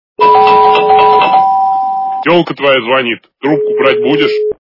» Звуки » Смешные » Звонок в дверь - Возьми трубку, твоя телка звонит.
При прослушивании Звонок в дверь - Возьми трубку, твоя телка звонит. качество понижено и присутствуют гудки.
Звук Звонок в дверь - Возьми трубку, твоя телка звонит.